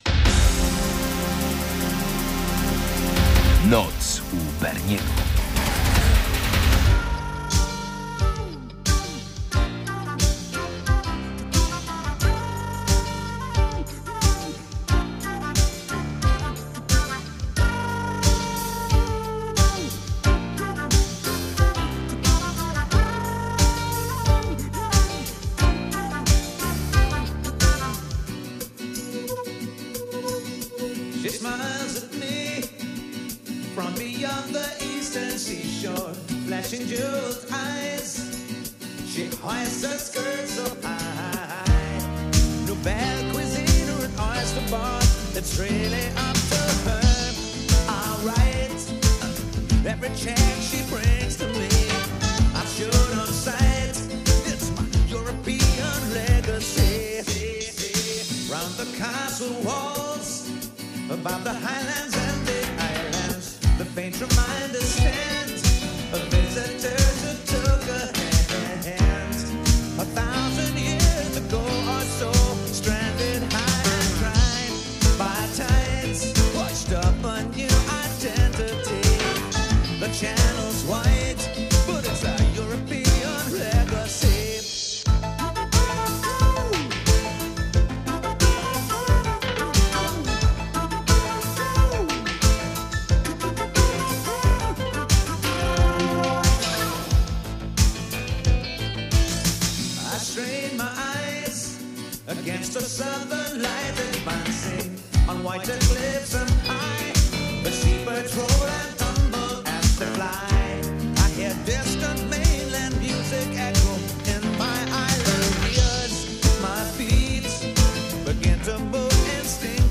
Dominujący gatunek: piosenki z 1984 roku